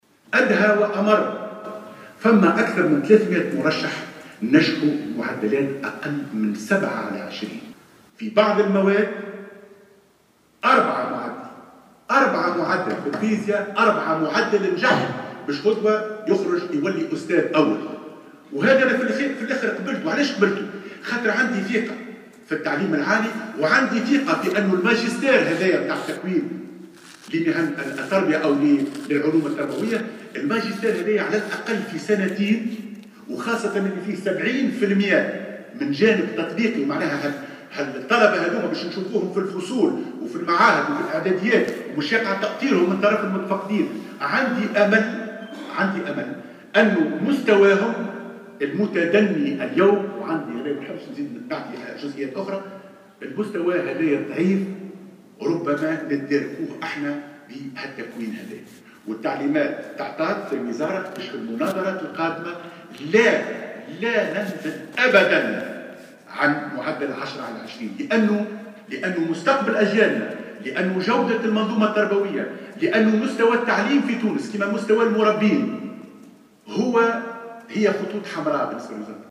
وأوضح بن سالم، خلال جلسة الاستماع إليه خلال الجلسة العامة بمجلس نواب الشعب، اليوم السبت، أن الأمل بات معقودا على الماجستير والتكوين الذي سيتلقاه المترشحون في مهن التربية، وخاصة على المستوى التطبيقي لتدارك هذه المستويات الضعيفة، لإنقاذ جودة التعليم في تونس، وفق تعبيره.